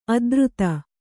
♪ adruta